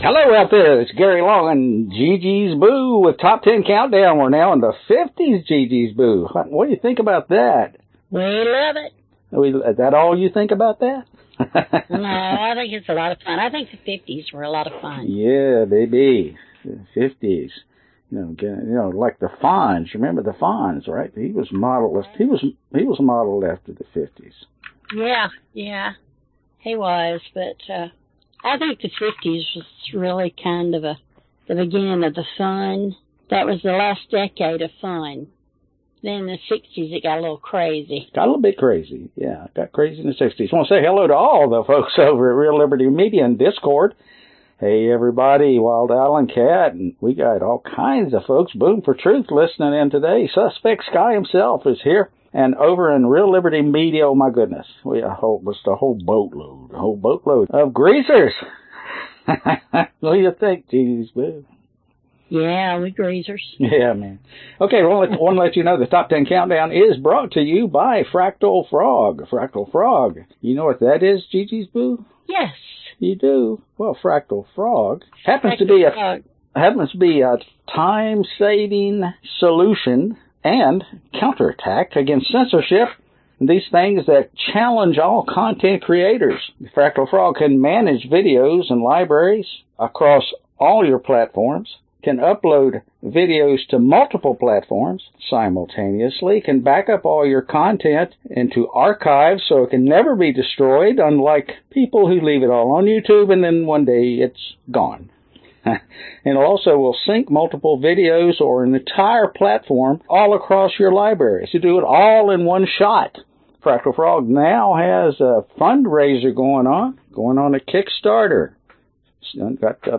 Top Ten Countdown - 1950 Genre Oldies